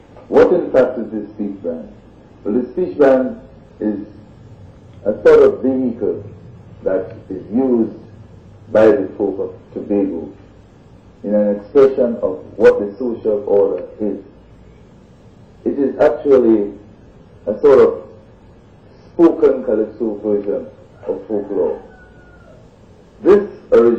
2 audio cassettes